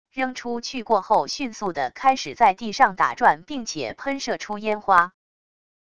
扔出去过后迅速的开始在地上打转并且喷射出烟花wav音频